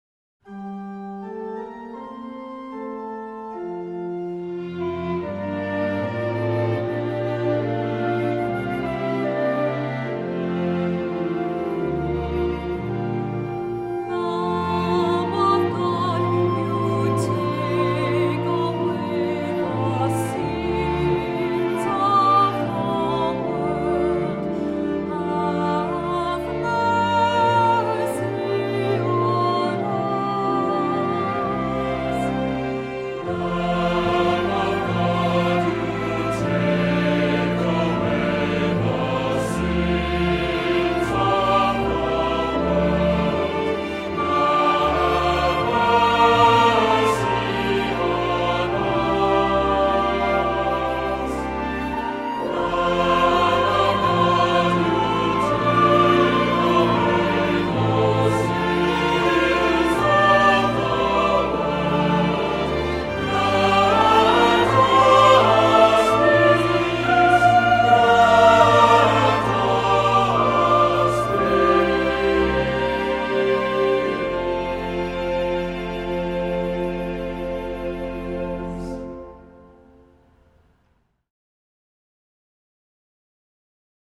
Voicing: Unison with descant; Assembly